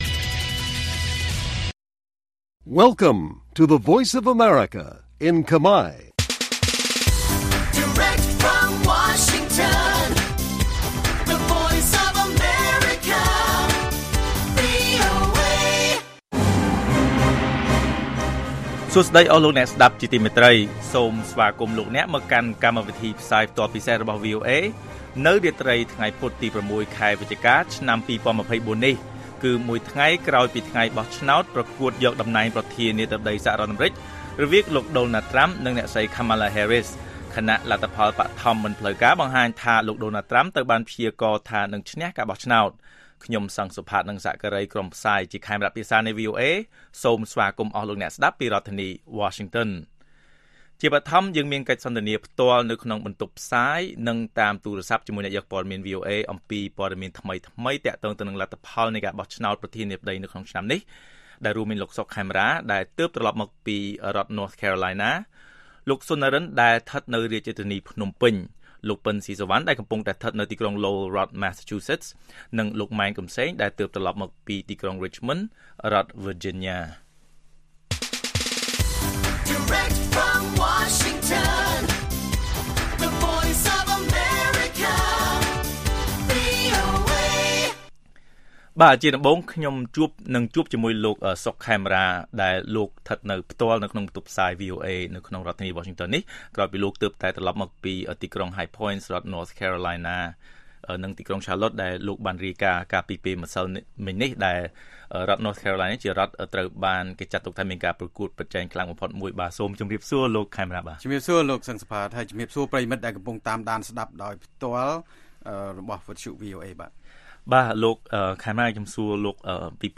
ព័ត៌មាននៅថ្ងៃនេះមាន កិច្ចសន្ទនាផ្ទាល់ជាមួយអ្នកយកព័ត៌មានវីអូអេស្តីពីការបោះឆ្នោតប្រធានាធិបតីអាមេរិកឆ្នាំ ២០២៤។